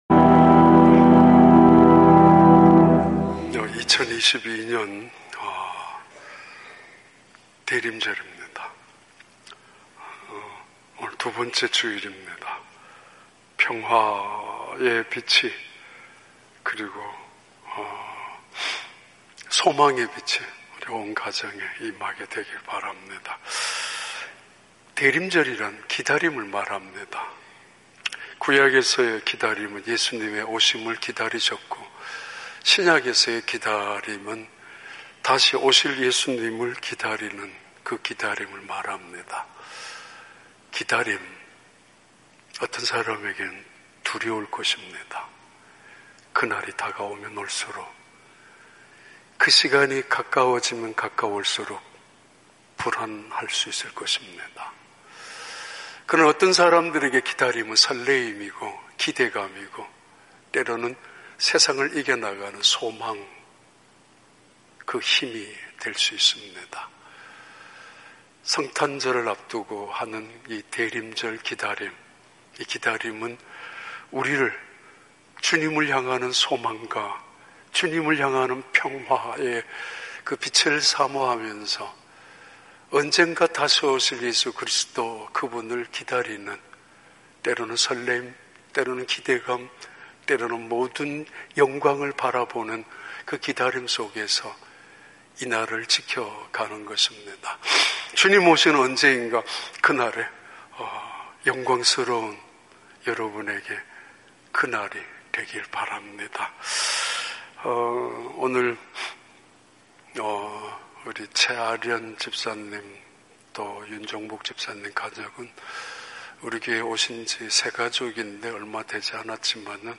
2022년 12월 4일 주일 3부 예배